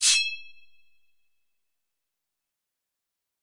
近战战斗的声音 " 剑的滑动
描述：用餐刀和匕首制成
标签： 冲突 论剑 战斗 金属 武士刀 骑士 战斗 中世纪 匕首 决斗
声道立体声